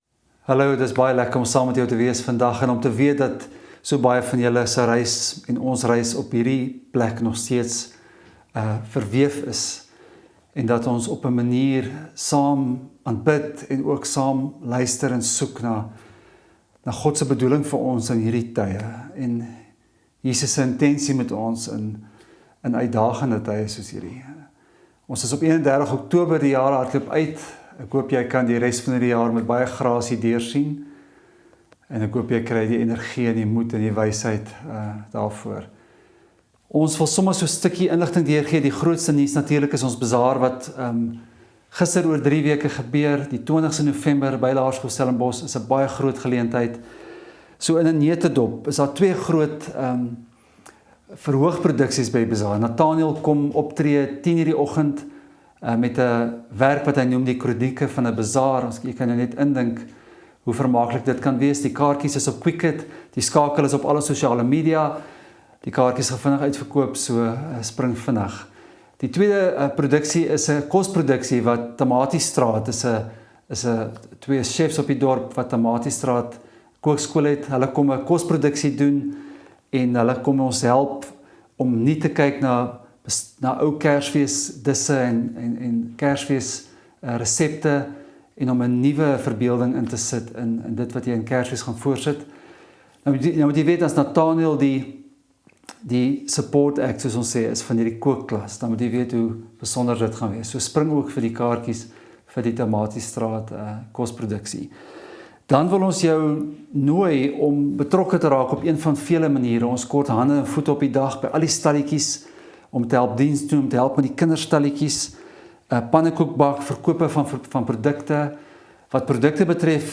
Preke